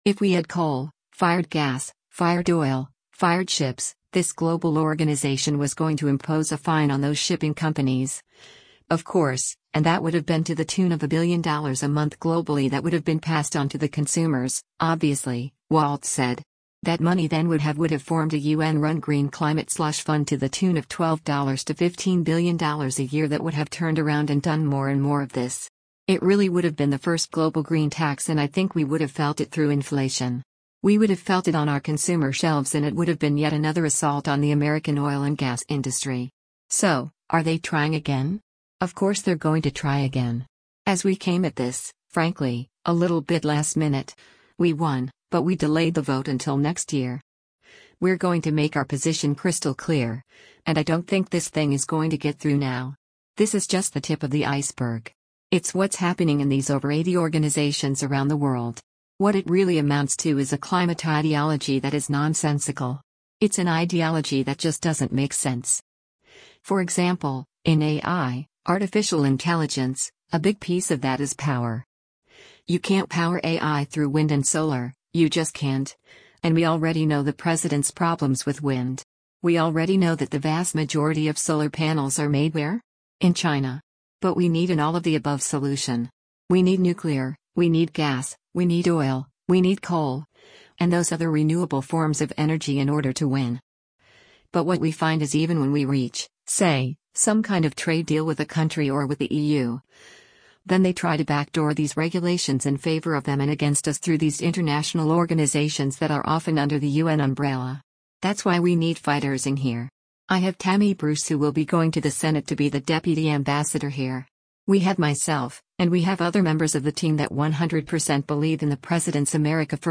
Waltz’s comments came during a lengthy exclusive on-camera interview in late October at the United Nations, where Breitbart News joined the newly-confirmed Ambassador for his first major interview since his U.S. Senate confirmation.